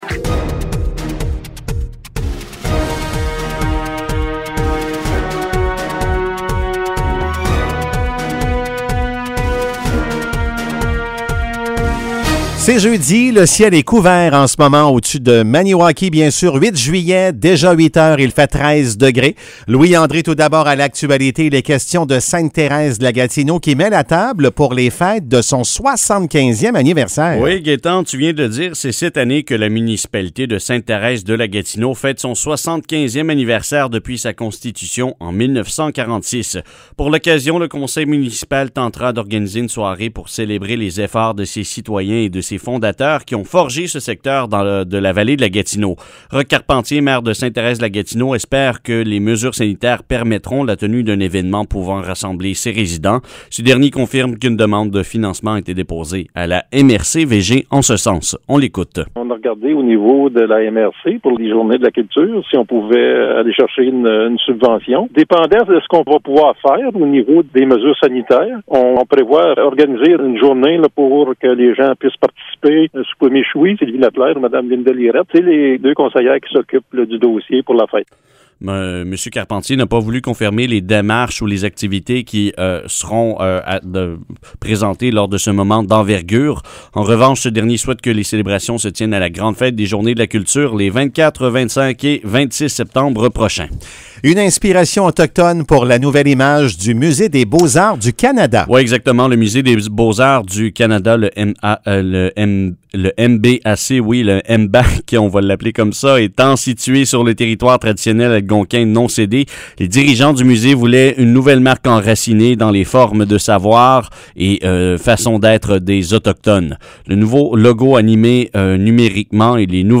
Nouvelles locales - 8 juillet 2021 - 8 h